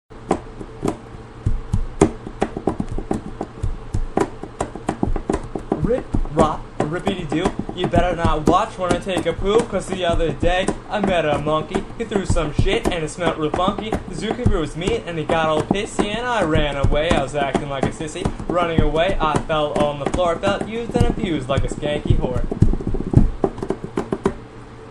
drums
vocals